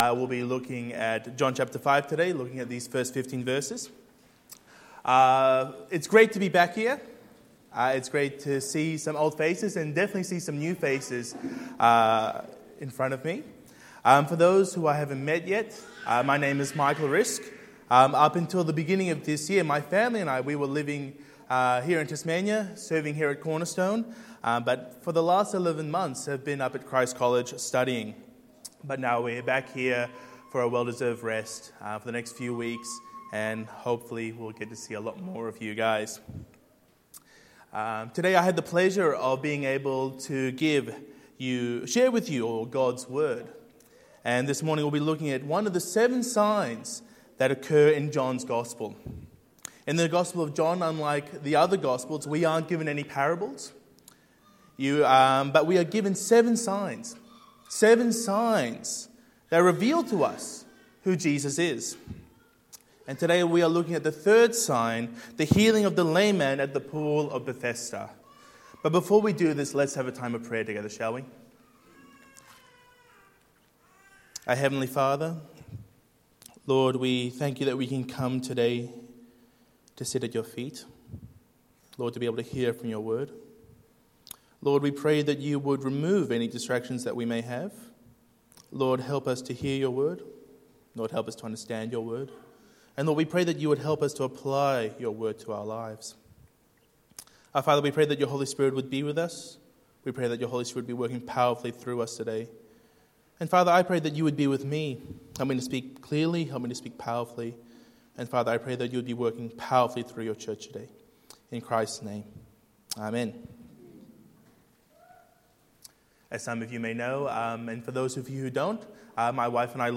John 5:1-15 Sermon